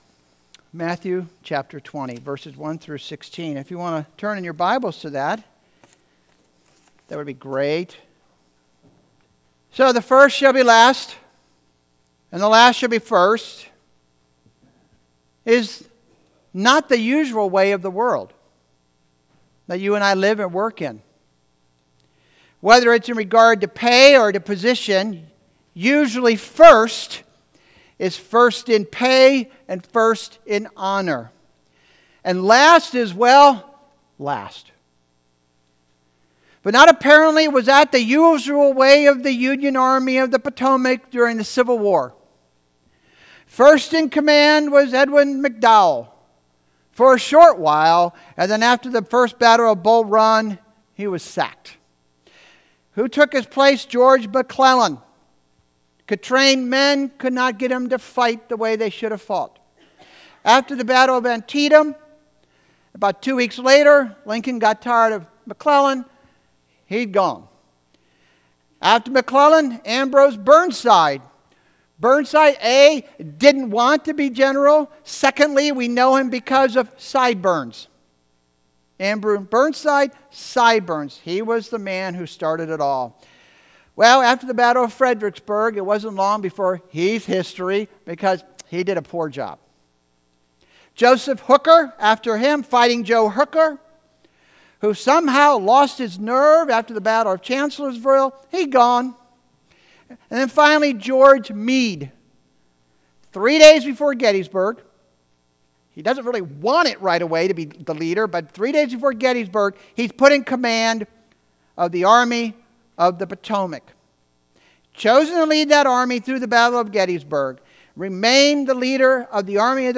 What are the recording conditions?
Matthew 20:1-16 Service Type: Sunday Morning « The Hall of Faithful Ones